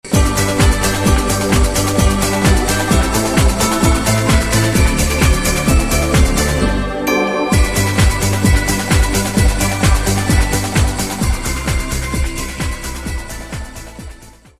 - Muzyka elektroniczna